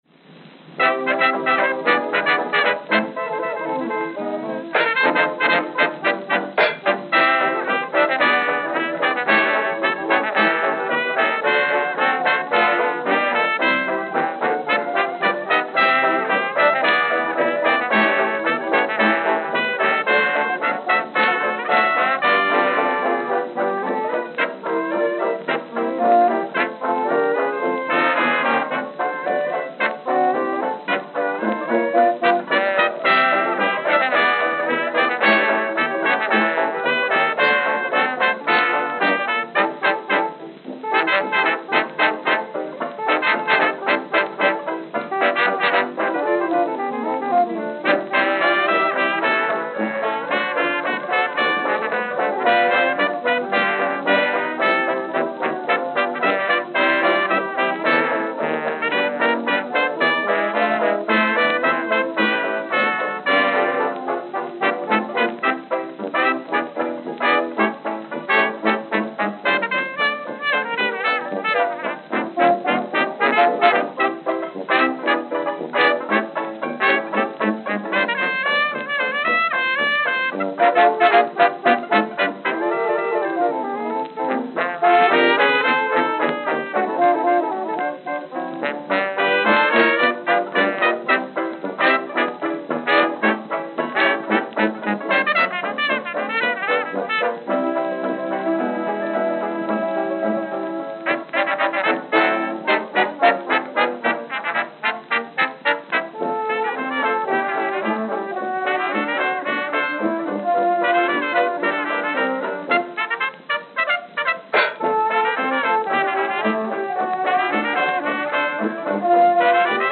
Electrical Recording Begins